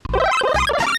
The Power-Up sound as heard in Super Smash Bros.
This media file is poor quality.
Specifics: Audio has background music
SSB_Power-Up_sound.wav